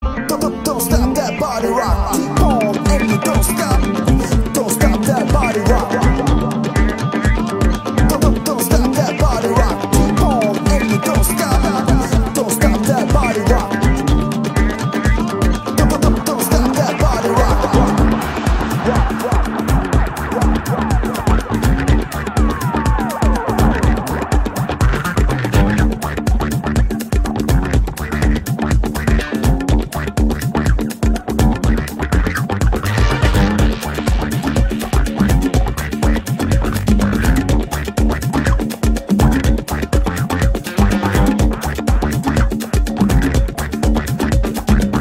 Catégorie Électronique